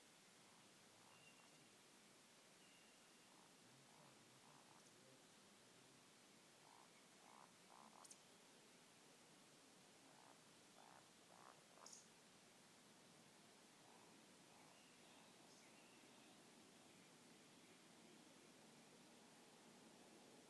вальдшнеп, Scolopax rusticola
Administratīvā teritorijaVentspils novads
СтатусСлышен голос, крики